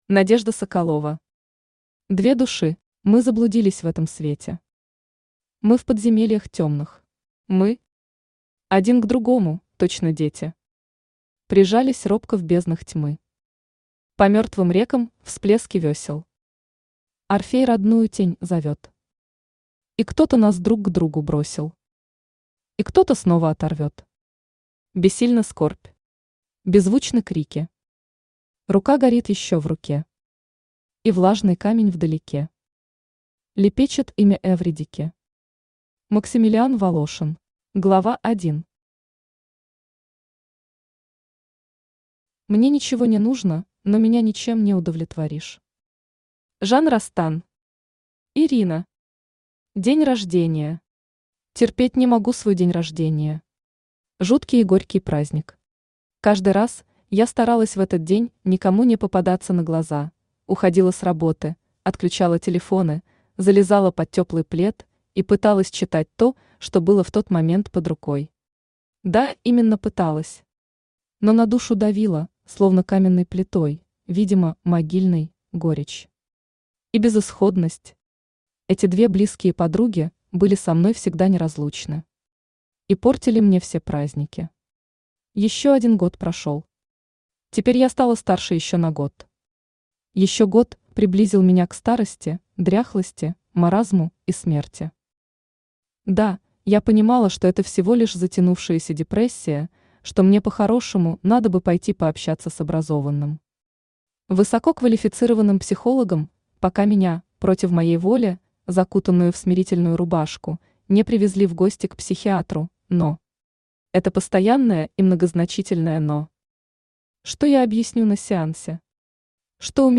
Аудиокнига Две души | Библиотека аудиокниг
Aудиокнига Две души Автор Надежда Игоревна Соколова Читает аудиокнигу Авточтец ЛитРес.